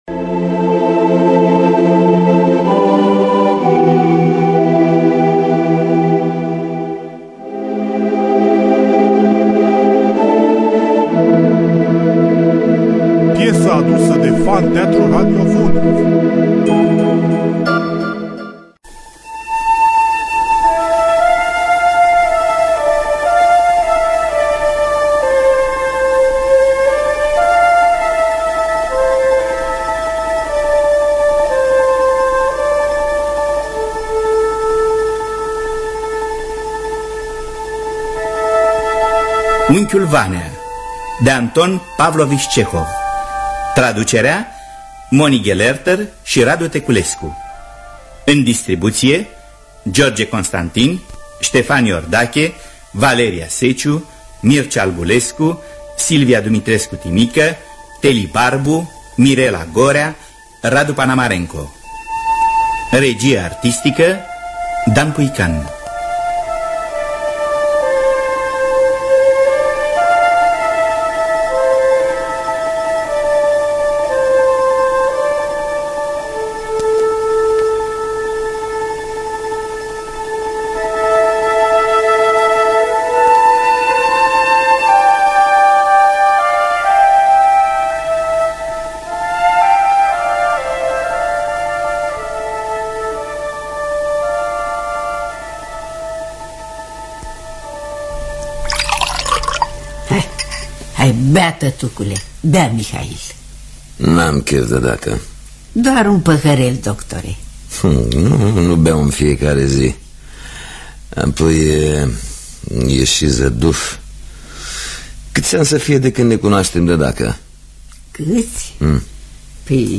Adaptarea radiofonică